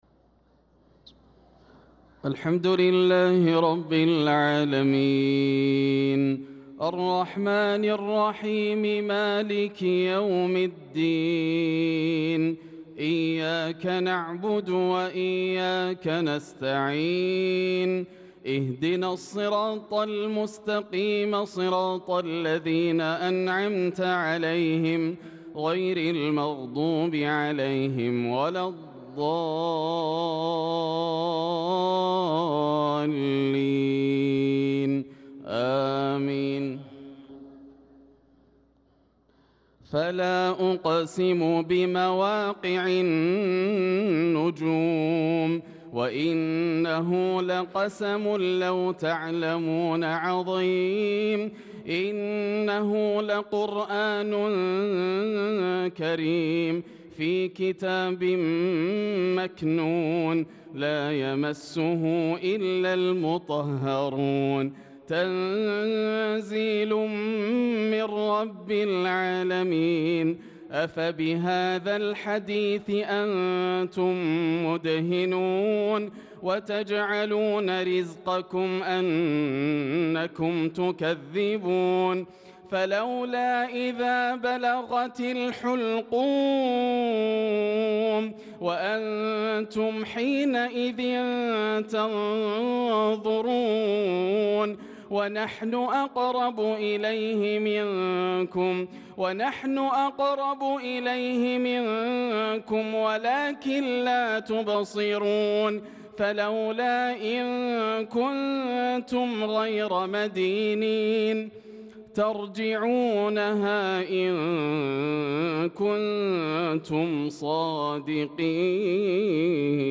صلاة العشاء للقارئ ياسر الدوسري 29 ذو القعدة 1445 هـ
تِلَاوَات الْحَرَمَيْن .